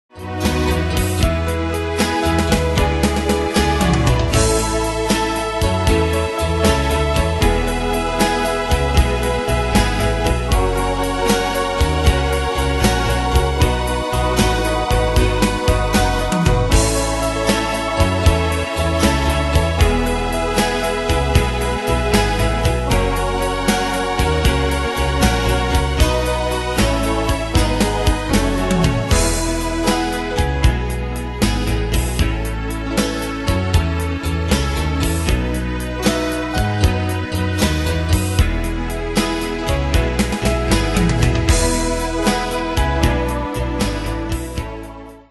Danse/Dance: Ballade Cat Id.
Pro Backing Tracks